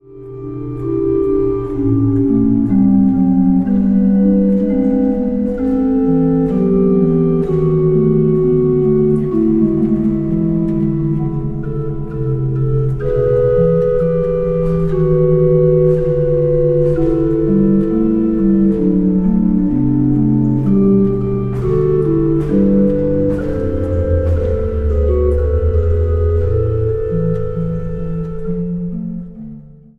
Zang | Gemengd koor
Instrumentaal | Orgel